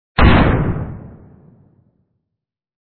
1_explosion.mp3